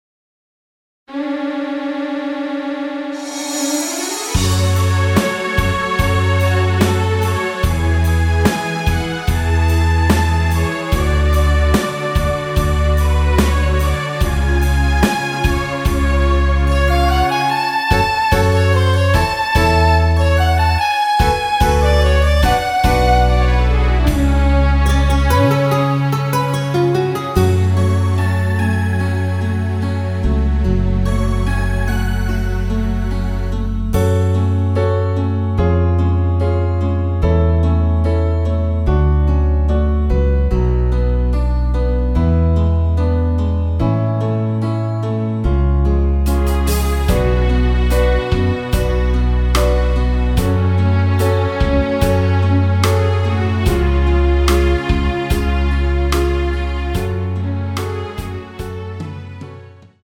원키에서(-1)내린 MR입니다.
F#m
앞부분30초, 뒷부분30초씩 편집해서 올려 드리고 있습니다.